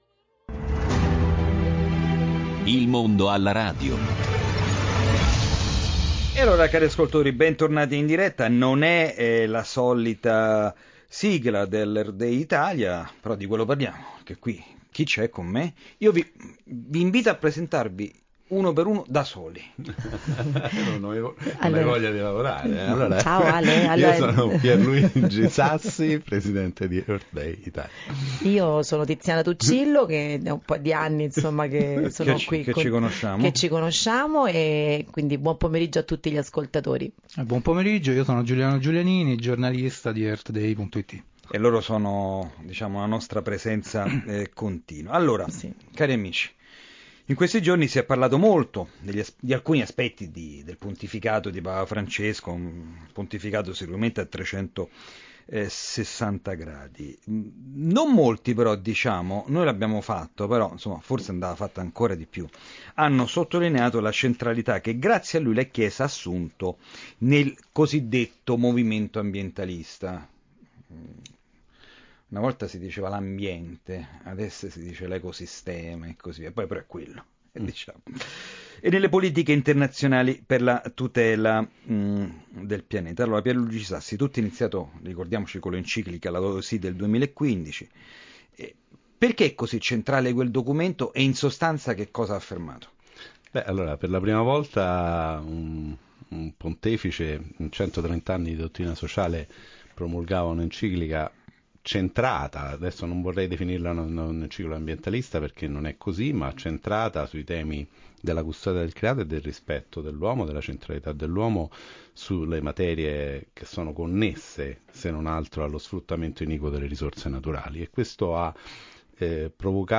podcast della puntata trasmessa da Radio Vaticana Italia